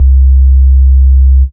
Sub Juno C1.wav